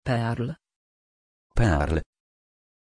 Pronunciation of Pearl
pronunciation-pearl-pl.mp3